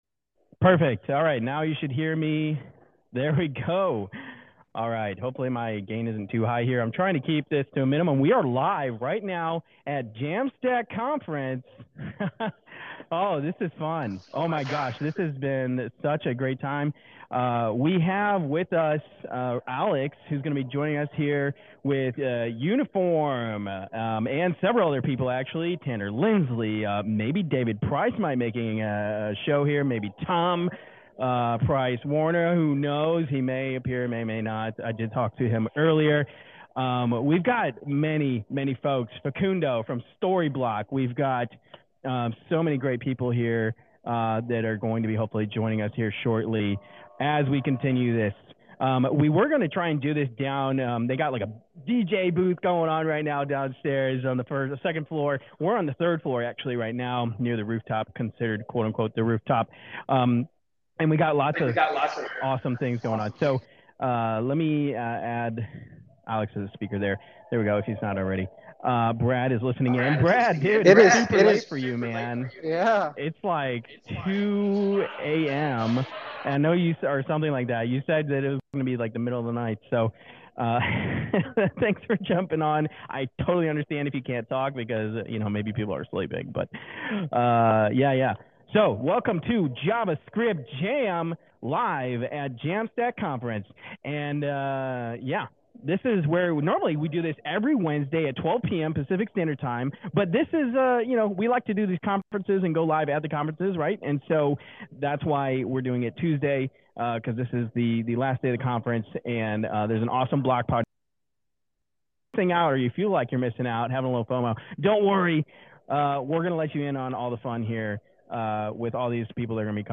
JavaScript Jam goes live from Jamstack Conf 2022, discussing edge computing, composable architectures, and the future of frameworks with Uniform's